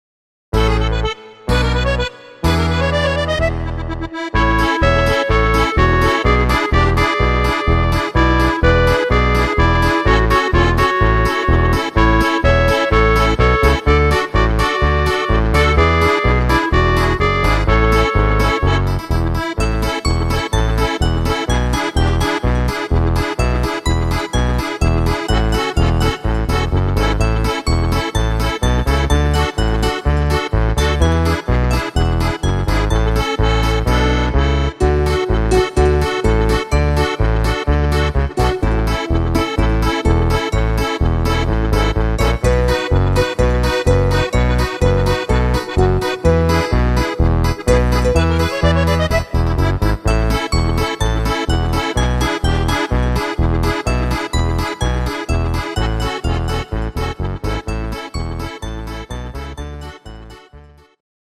inst. Klarinette